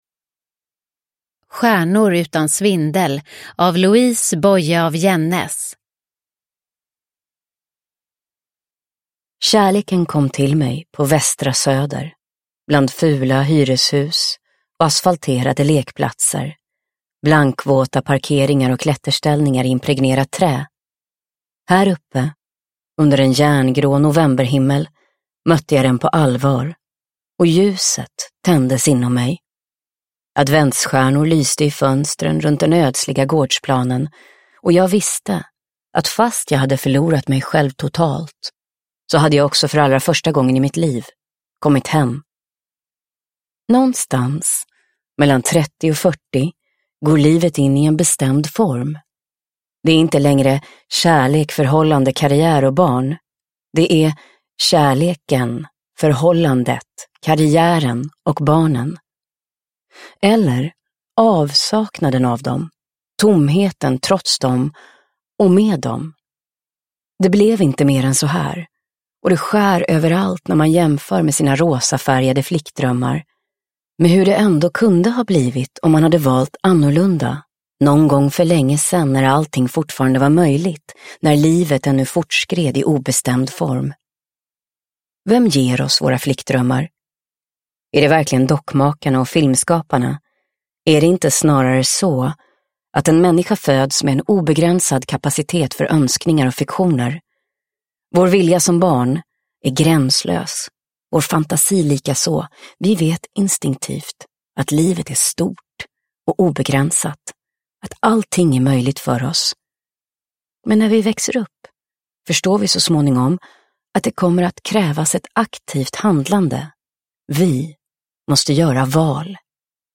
Stjärnor utan svindel – Ljudbok – Laddas ner
Uppläsare: Anja Lundqvist